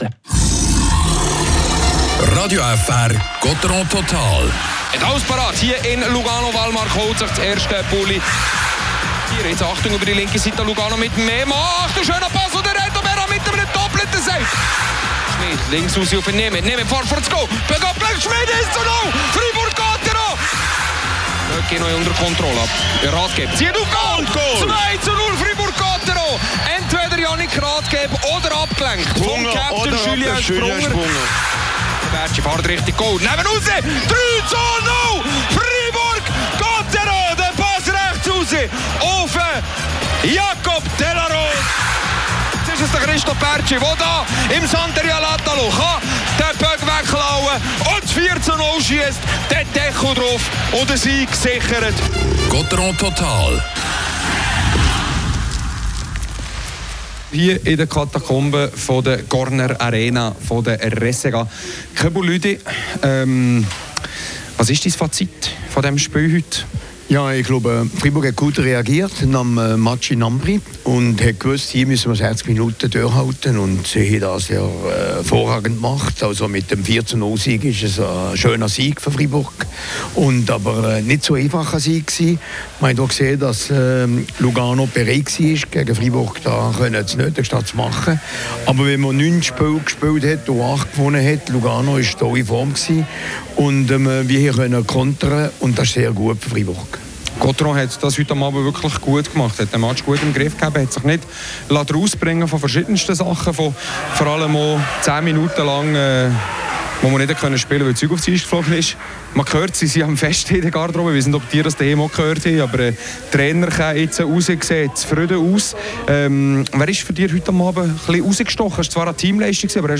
sowie die Interviews mit Julien Sprunger und Reto Berra hört ihr hier.